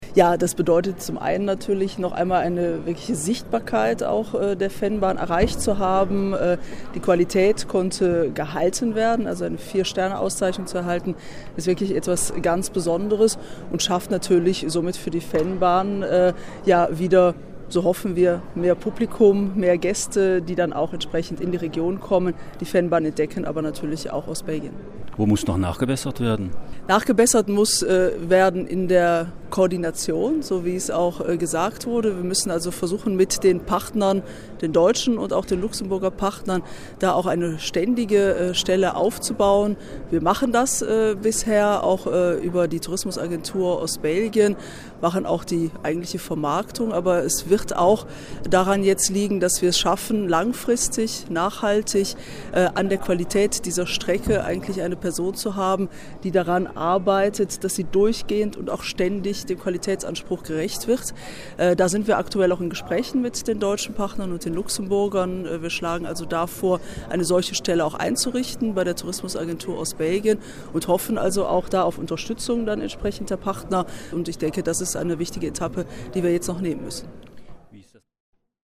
Soweit die Tourismusministerin der Deutschsprachigen Gemeinschaft Isabelle Weykmans bei der Internationalen Tourismus-Börse in Berlin, die sich auch über eine wachsende Nutzung des Vennbahn-Radweges freuen darf, wie sie sagt.